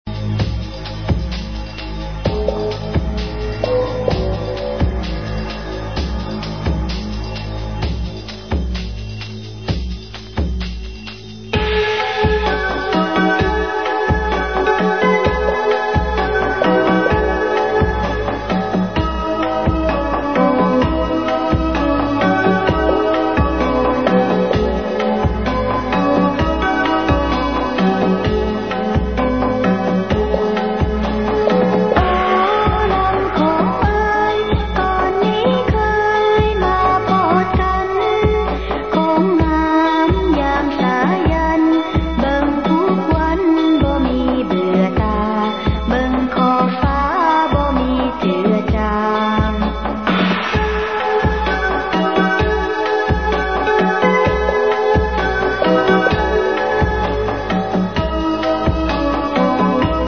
Музыка для медитации